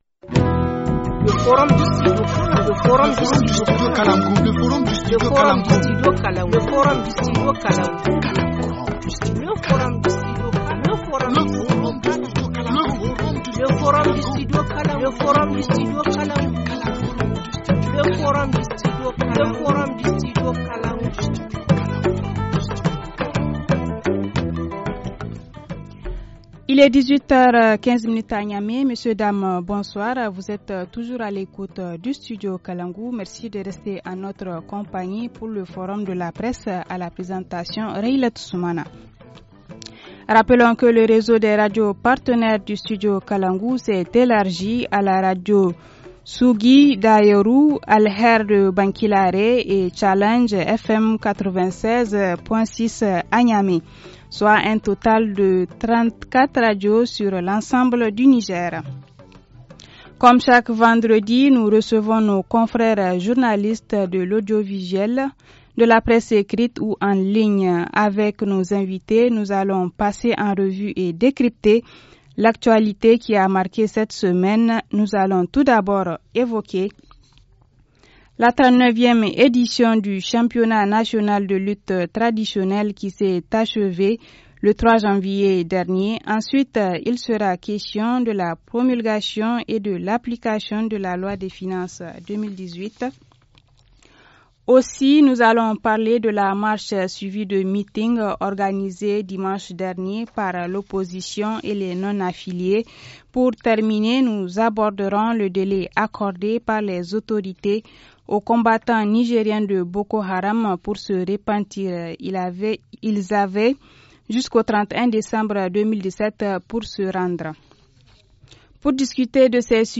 journaliste indépendant